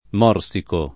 morsico [ m 0 r S iko ], ‑chi